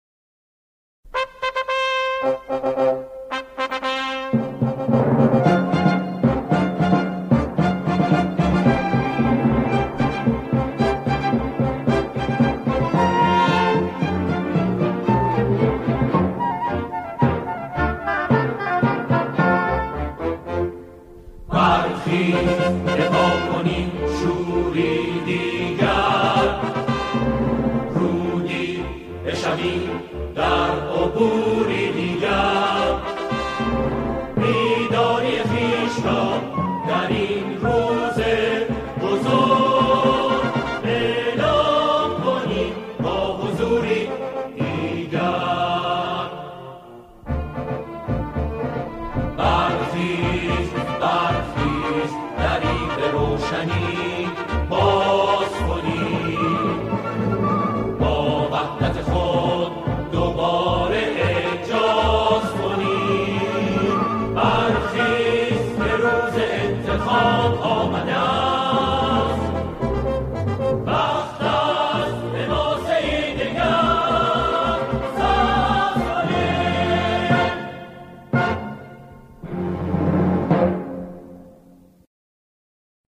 قطعه کوتاه